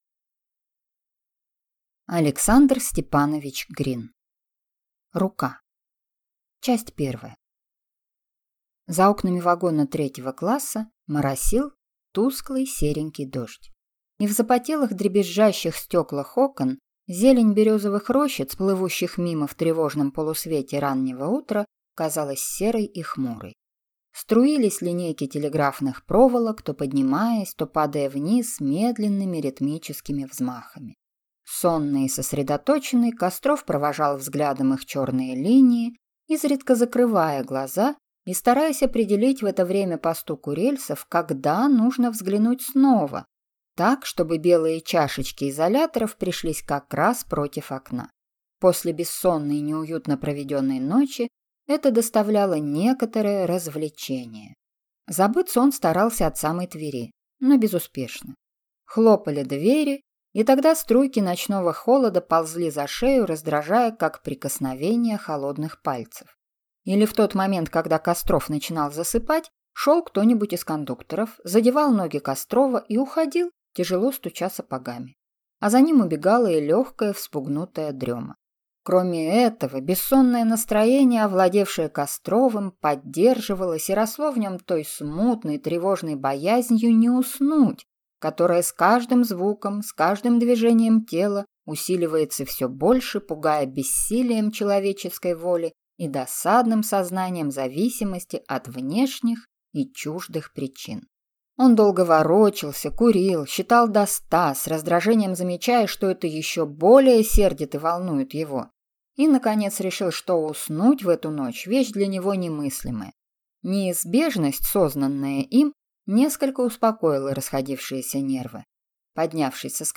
Аудиокнига Рука | Библиотека аудиокниг
Прослушать и бесплатно скачать фрагмент аудиокниги